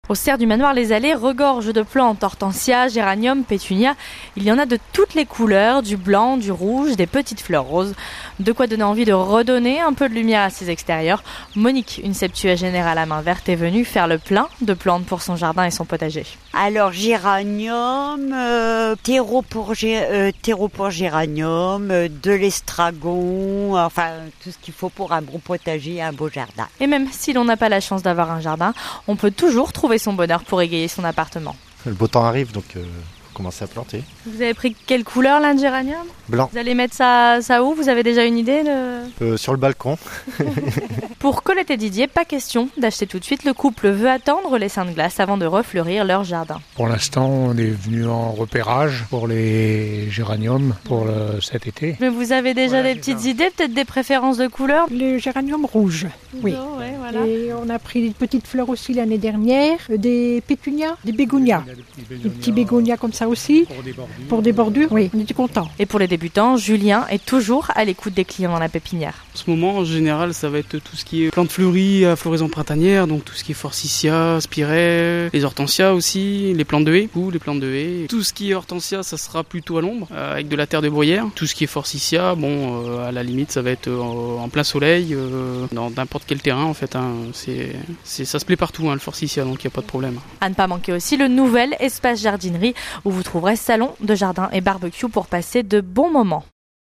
Reportage dans les allées fleuries des Serres du Manoir à Rang du Fliers !